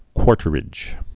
(kwôrtər-ĭj)